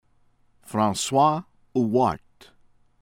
HORTEFEUX, BRICE BREES    OHR-teh-fuh